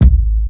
Kick_25.wav